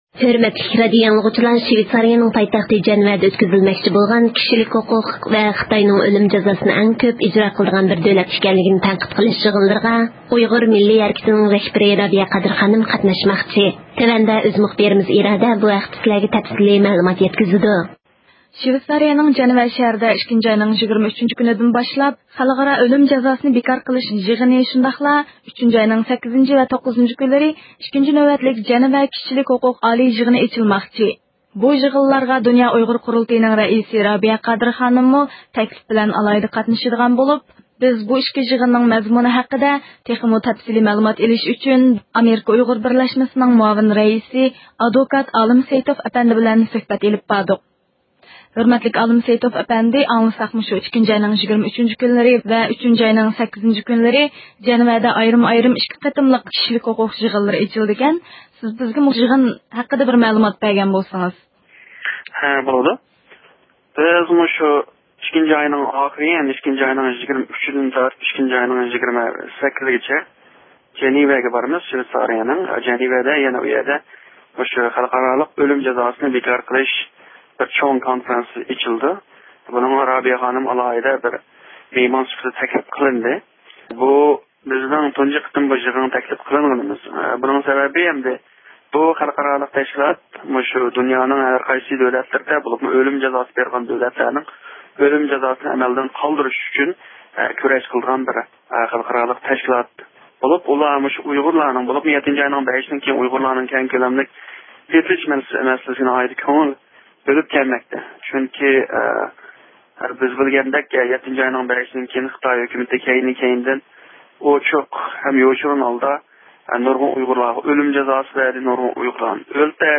سۆھبەت ئېلىپ باردۇق